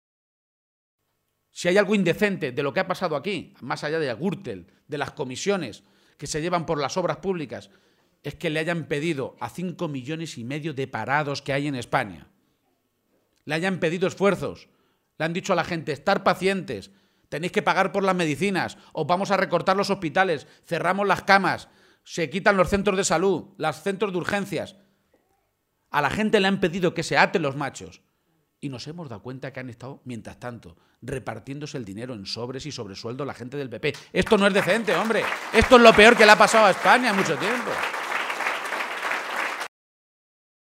Momento del acto público en Montiel